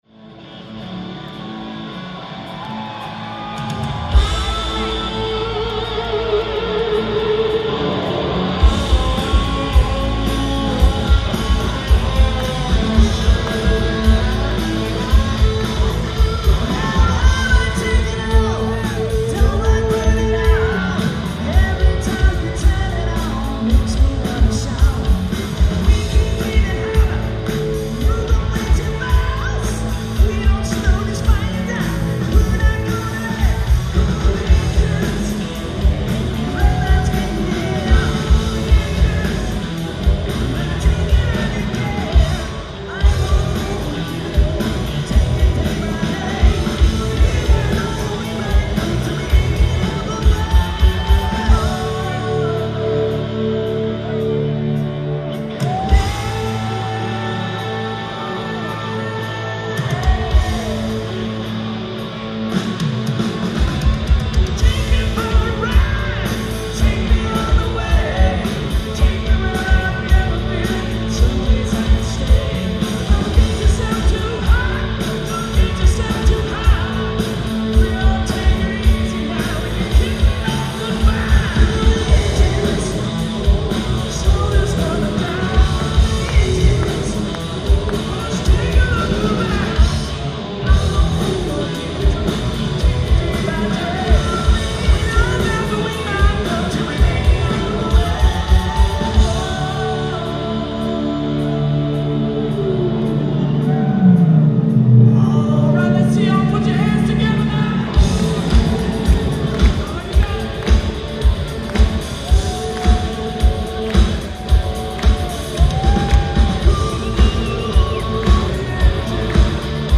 Sound Advice Amphitheatre
Source: Audience DAT Master